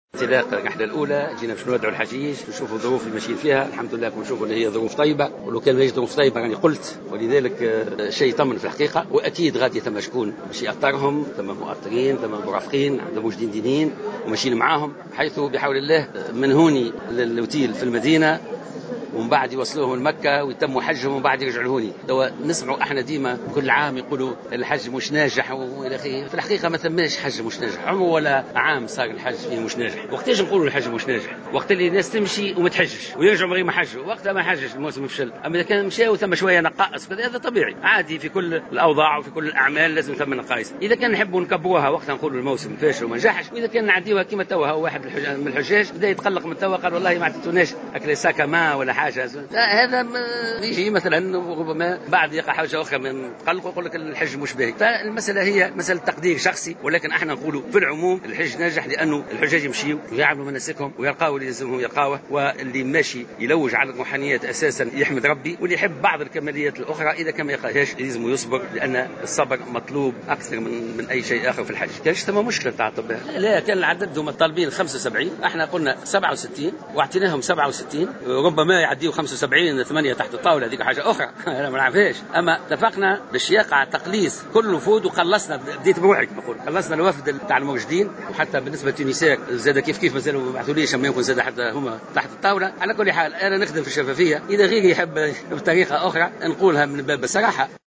قال وزير الشؤون الدينية محمد خليل خلال حضوره اليوم الاثنين 22 أوت 2016 بمطار الحبيب بورقيبة الدولي بالمنستير للاشراف على انطلاق أولى رحلات الحجيج إلى البقاع المقدسة إن هناك مرافقين للحجيج "تحت الطاولة".